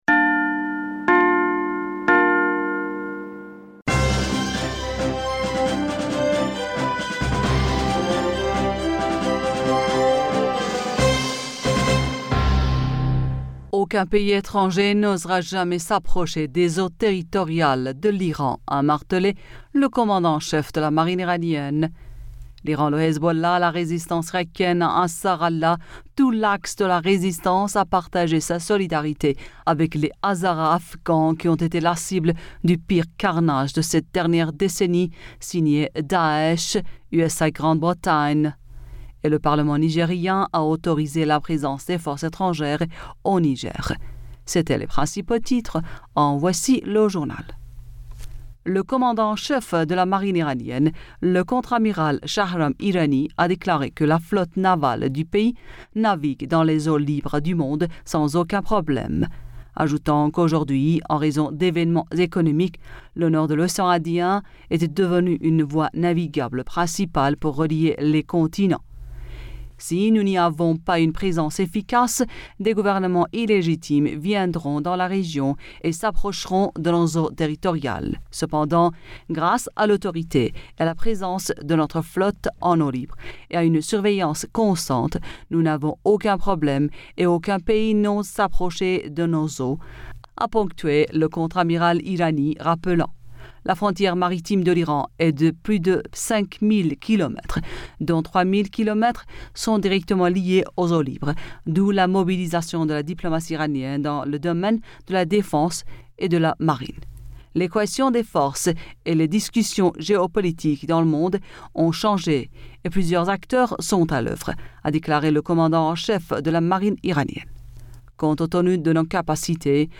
Bulletin d'information Du 23 Avril 2022